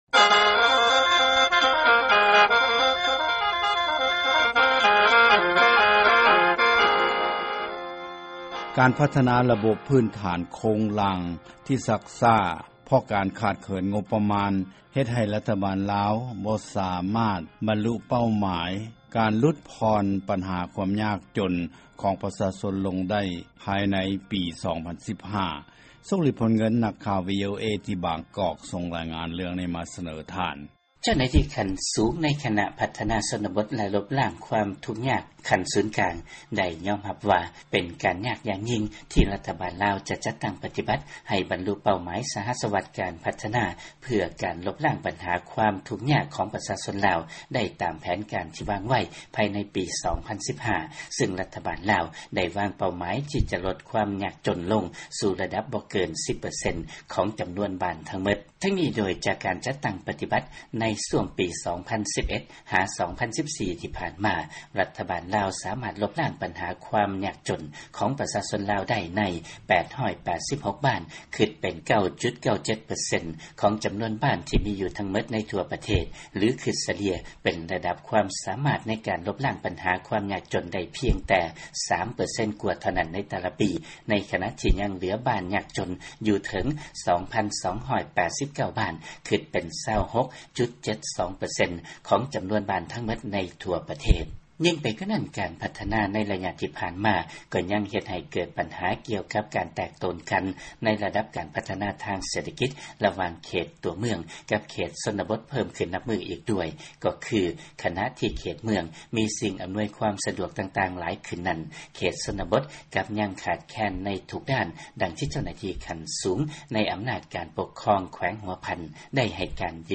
ຟັງລາຍງານ ລາວ ບໍ່ສາມາດບັນລຸເປົ້າໝາຍ ການຫລຸດຜ່ອນບັນຫາ ຄວາມທຸກຍາກຂອງປະຊາຊົນໄດ້ ພາຍໃນປີ 2015.